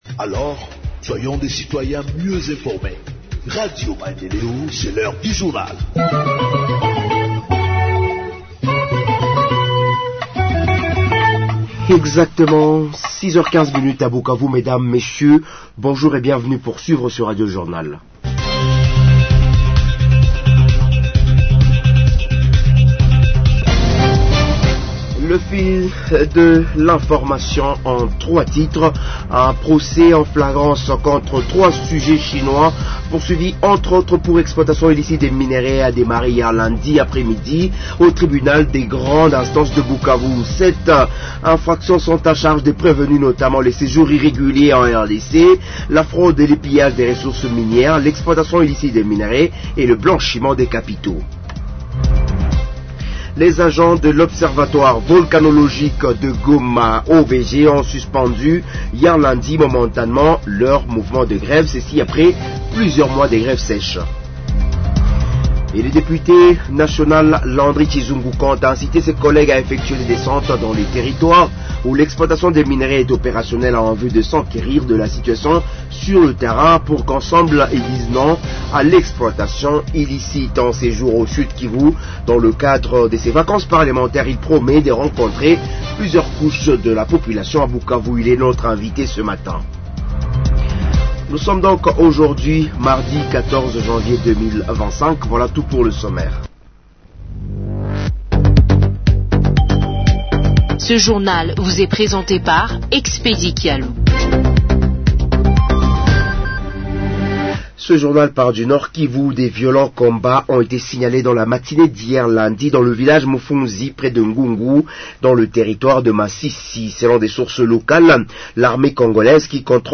Journal Français du mardi 14 janvier 2025 – Radio Maendeleo